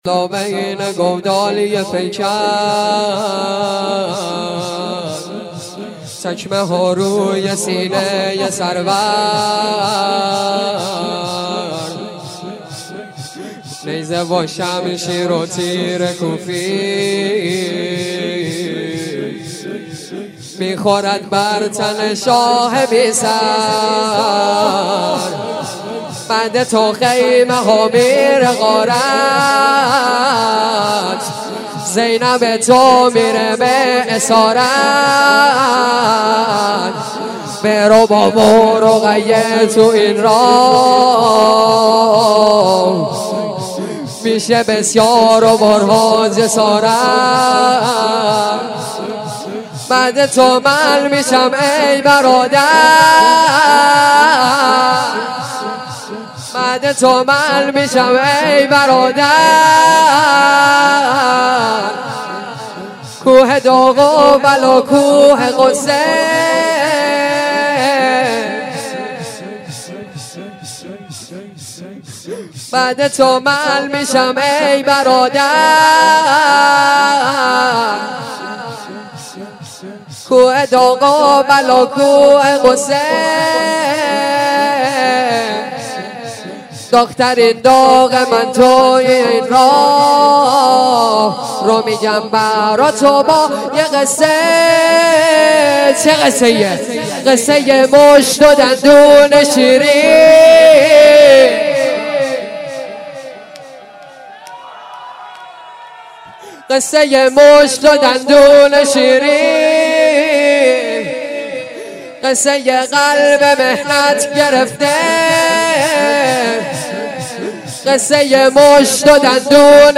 لطمه زنی